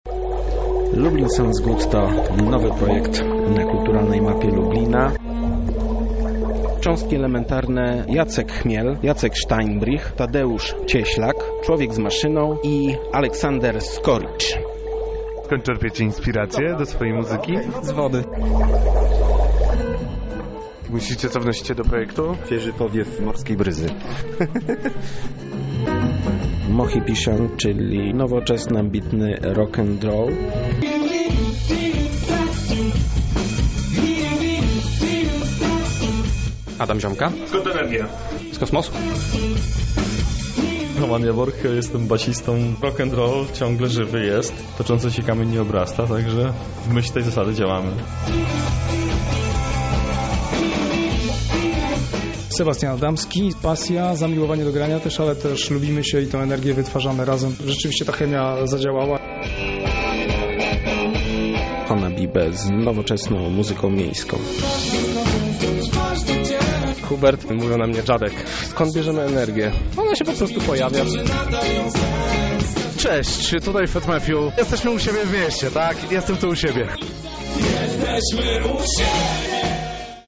Lublin – to brzmi dobrze. Muzyczne improwizacje, rock’n’roll i muzykę miasta mogliśmy usłyszeć w Chatce Żaka
W minioną sobotę w ramach projektu Lublin Sounds Good odbyły się trzy koncerty. Wszystkie w Inkubatorze Medialno-Artystycznym UMCS.